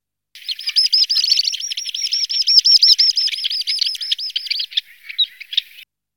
Bécasseau minute
Calidris minuta